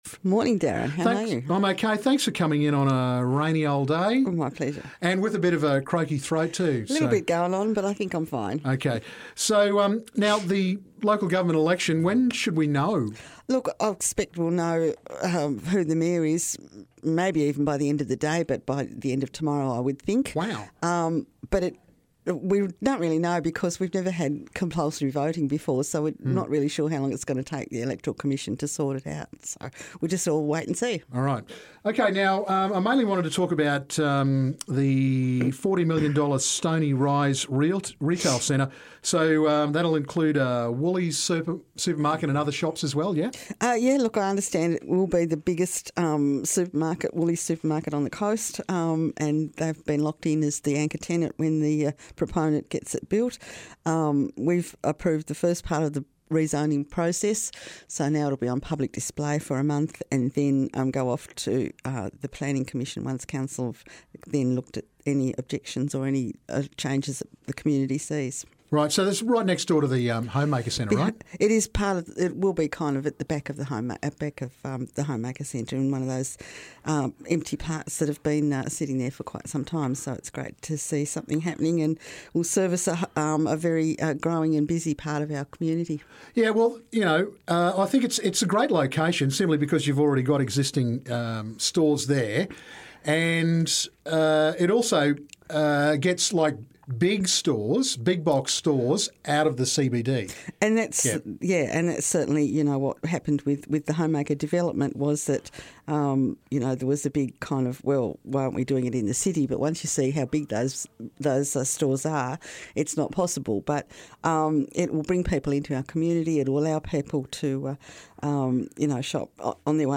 Devonport Mayor Annette Rockliff talks about the Stoney Rise commercial development, Waterfront Park and the Spring Fling.